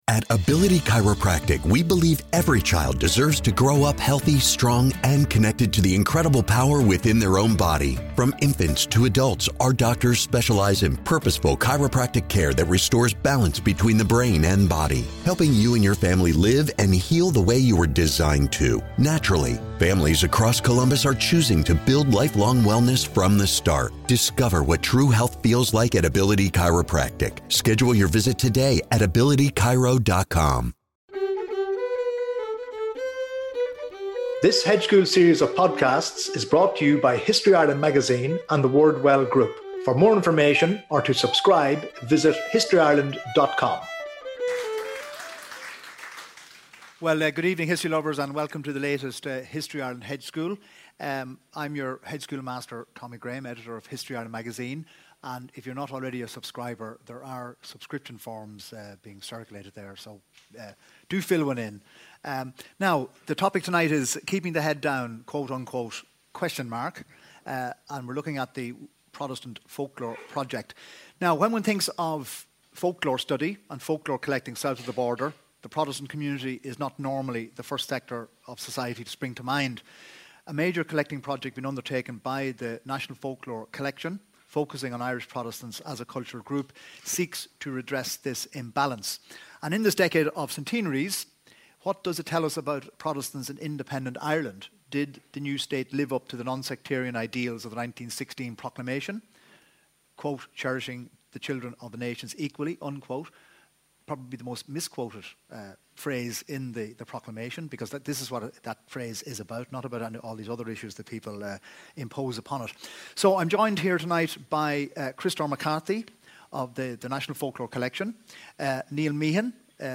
14th September 2017 @ Cavan County Museum, Virgina Road, Ballyjamesduff, Co Cavan.